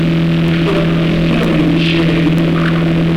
TRANSCOMUNICAZIONE CON LASER- ANTEPRIMA ESPERIMENTO SCIENTIFICO
Questo esperimento è all'avanguardia nella ricerca psicofonica in quanto la manifestazione vocale non interferisce con un eventuale spostamento meccanico o di altro tipo perchè è senza l'ausilio di microfono o testina magnetica in quanto la registrazione avviene in modo digitale.
Analisi spettrografica del fileoriginale ////////////// //////////////////Analisi del file  pulito dal rumore di sottofondo del laser